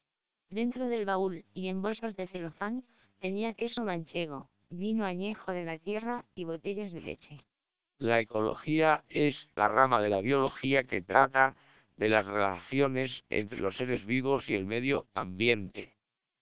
Also, all independent listeners preferred SPR, noting its significantly higher speech quality and intelligibility.